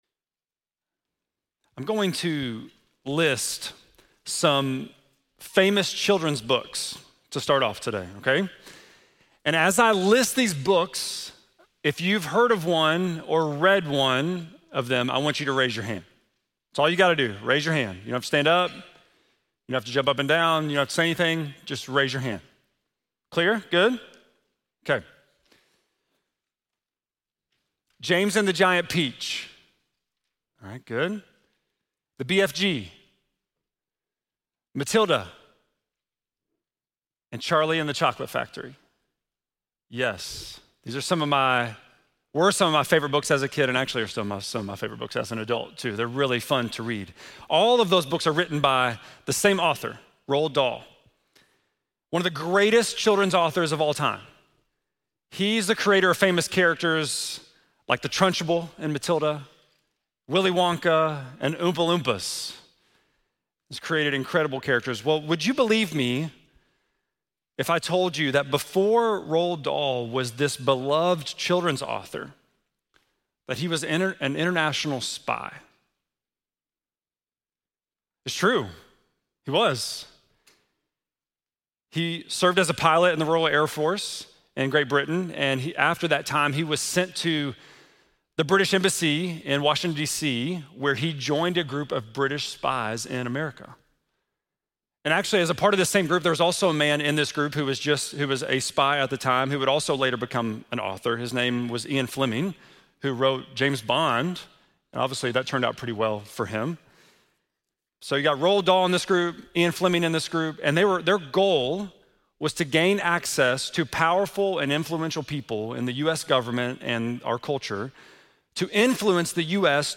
3.29-sermon.mp3